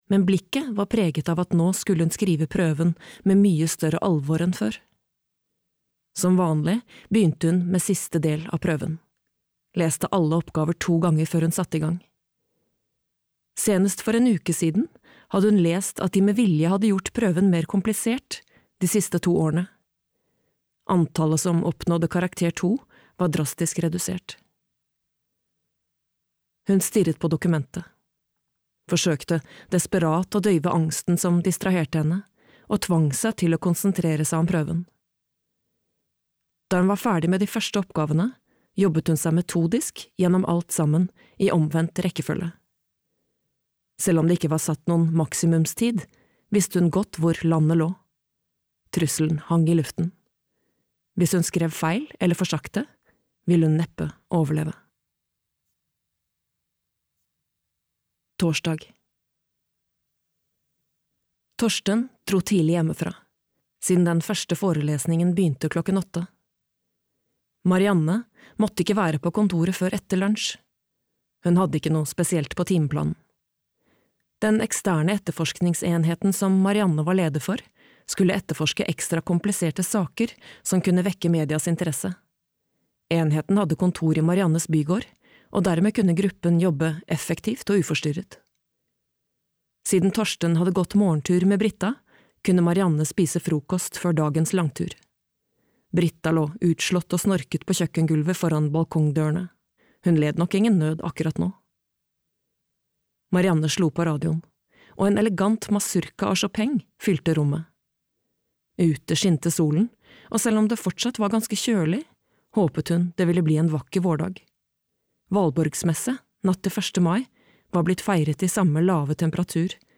De første tolv (lydbok) av Denise Rudberg